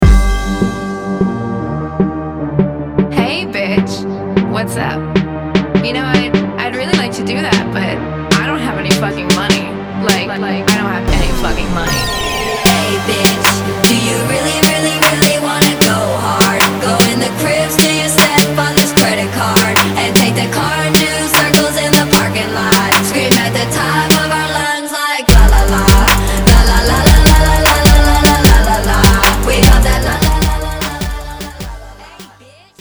• Качество: 320, Stereo
женский голос
Trap
Bass
Стиль: rap, trap